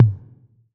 6TOM LW 2.wav